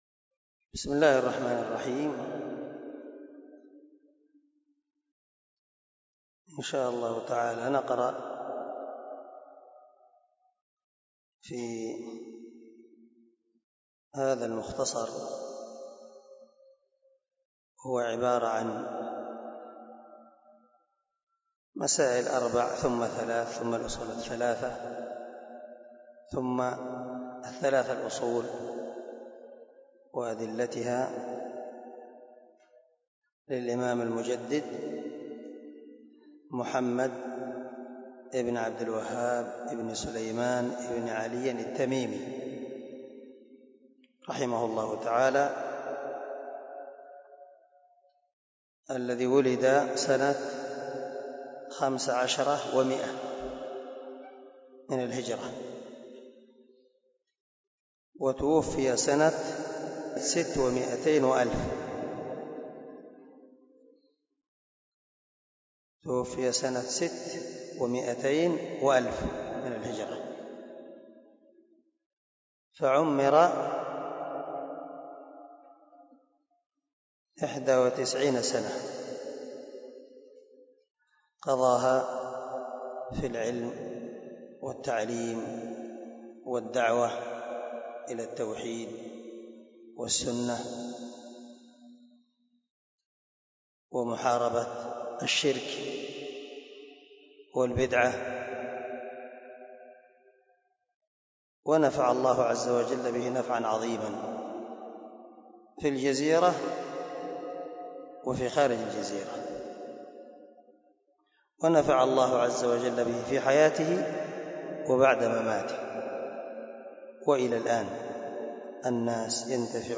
🔊 الدرس 1 من شرح الأصول الثلاثة